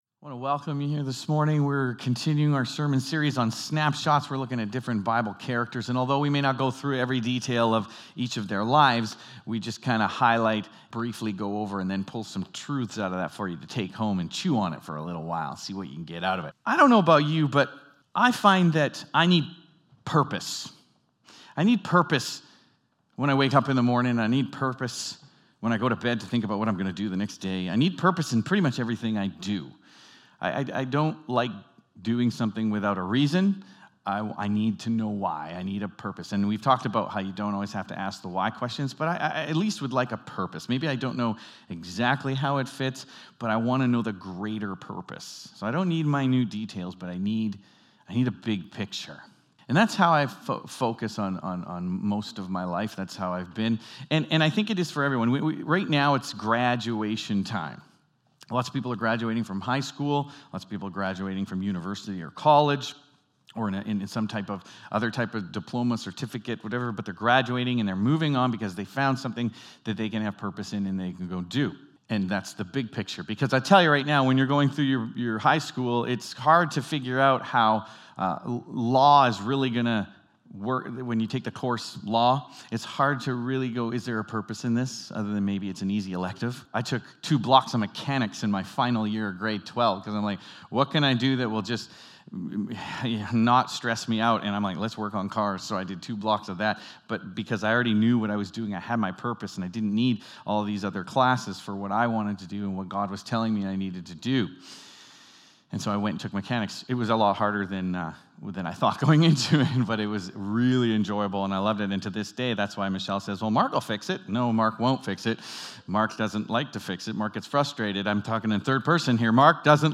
Sermons | Westwinds Community Church